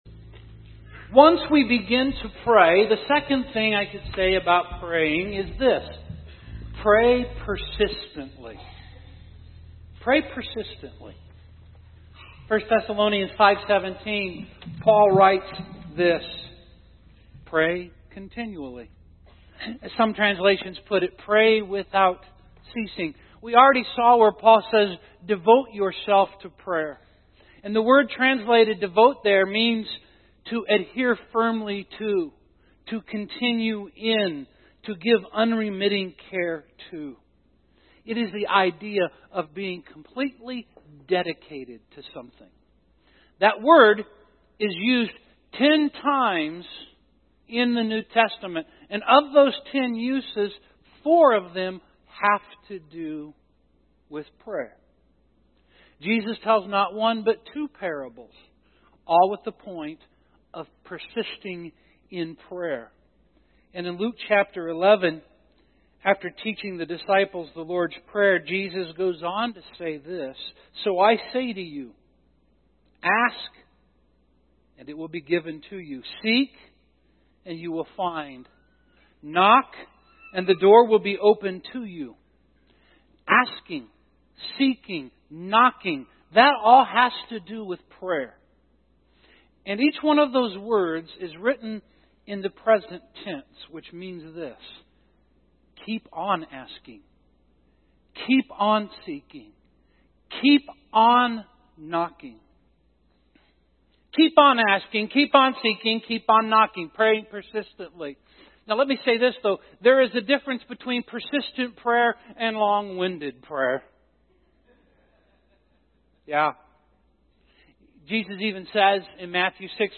Life, culture, Satan’s schemes, and our sinful nature seek to cut us off from prayer, and there is no more effective way of disconnecting us from our Savior. In this sermon we not only talk about how we should pray, but we spend time in prayer during the service.